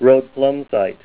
Help on Name Pronunciation: Name Pronunciation: Rhodplumsite + Pronunciation